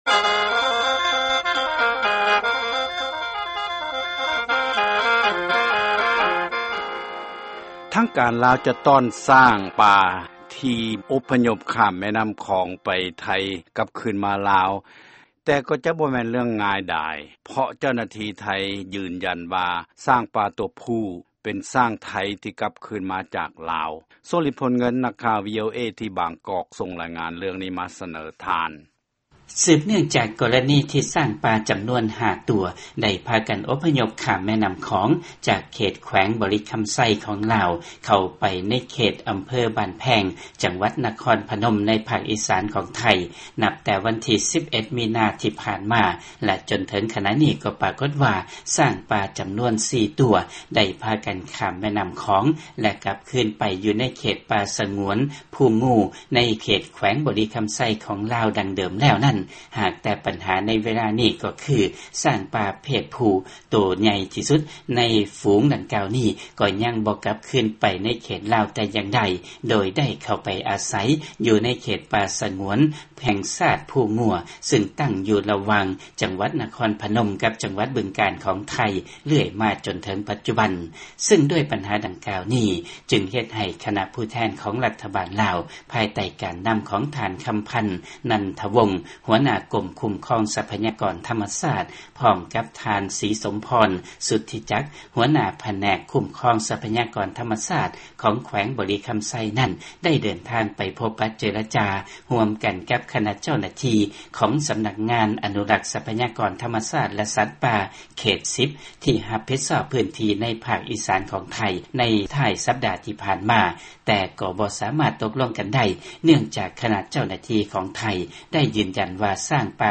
ຟັງລາຍງານເລື້ອງຊ້າງລາວແລະໄທ